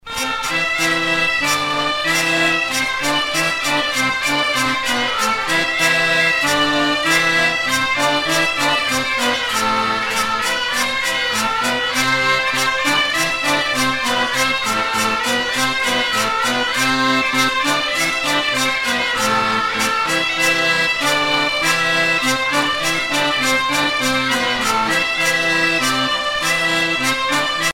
Usage d'après l'analyste gestuel : danse ;
Genre brève
Pièce musicale éditée